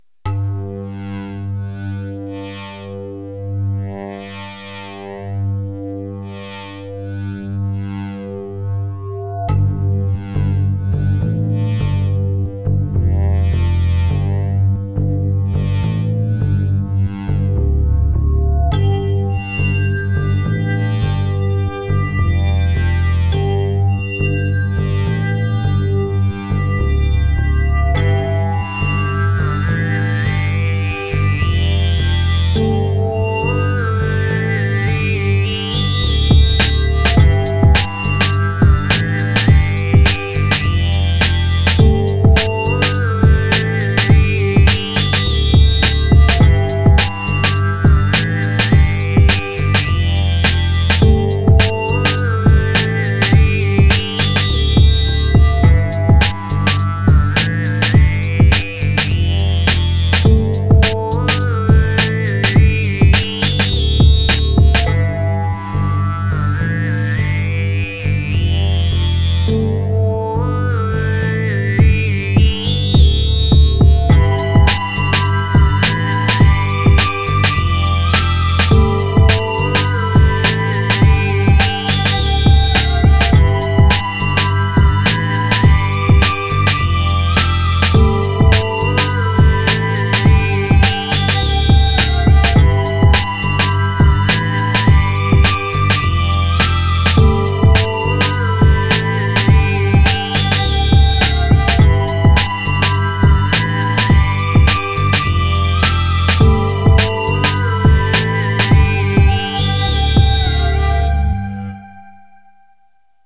Voilà un ptit son que j'ai fait avec le Korg Wavestation si ça intéresse qq'un... Toutes les nappes et mélodies sont jouées sur le WS, les drums et la basse sont des samples séquencés sur MPC1000.
Bon d'accord c'est pas un synthé prévu pour le hip-hop à la base, mais je voulais voir ce que ça donnait :tourne: